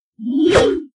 bibi_swing_03.ogg